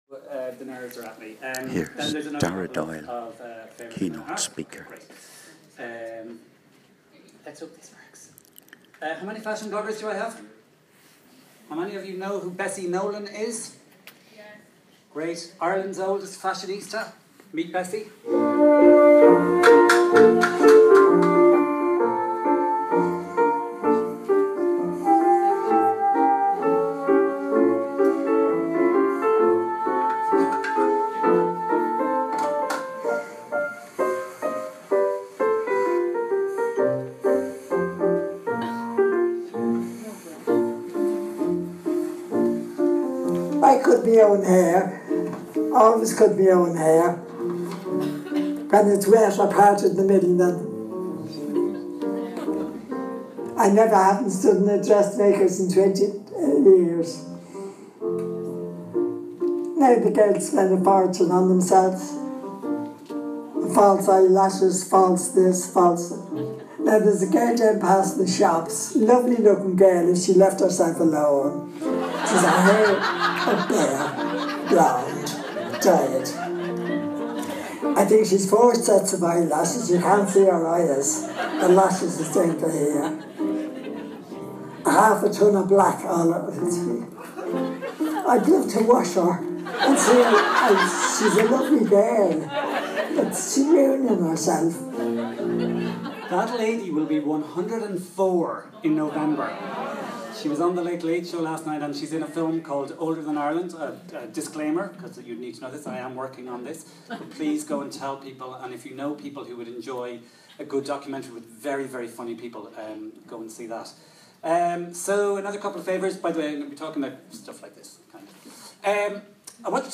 Irish Bloggers' Conference Cork yesterday